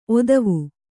♪ odavu